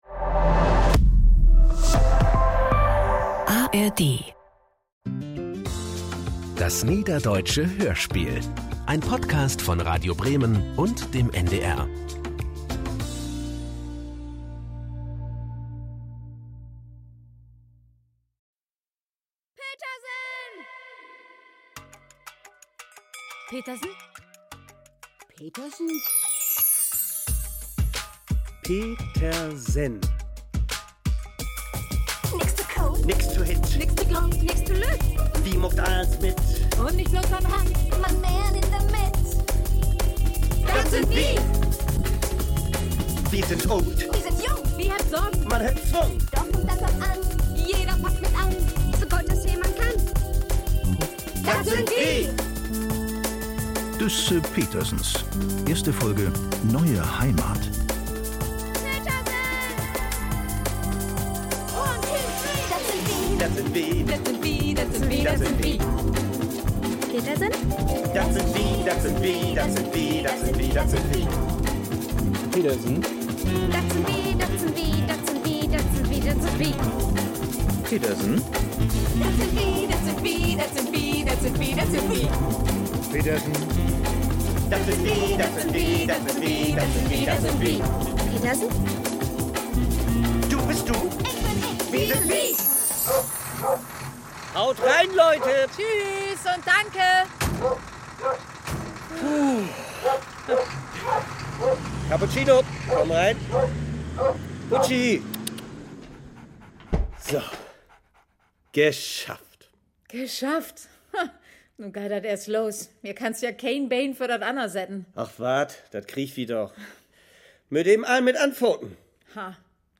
Niederdeutsches Hörspiel